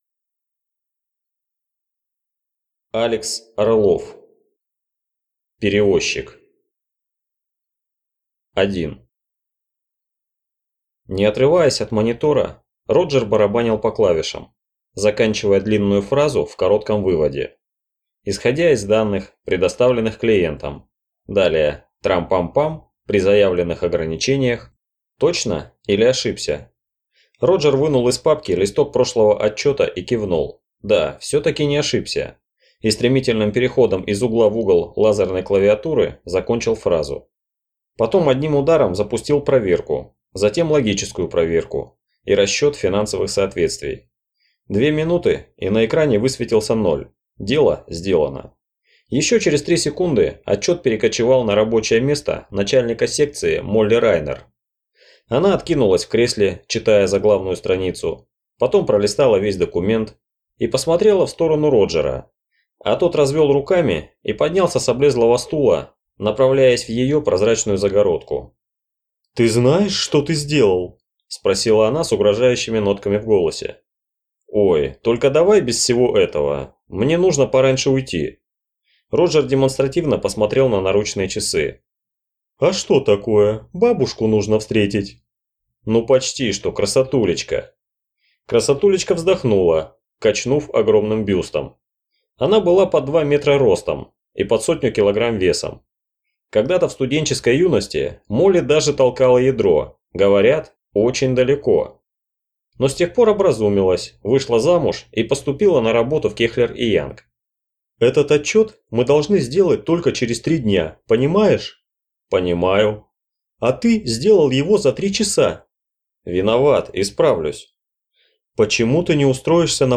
Аудиокнига Перевозчик | Библиотека аудиокниг